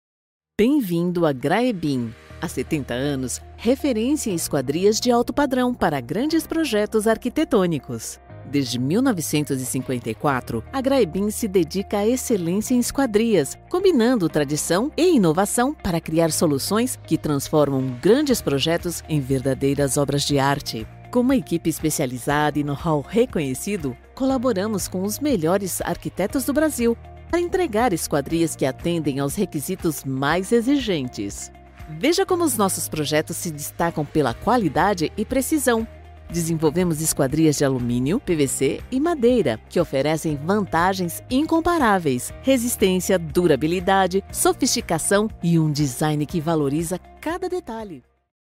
Corporate Videos
My voice is sweet, kind, friendly and conveys confidence and assurance.
Contralto
ConversationalFriendlyConfidentSincerePositive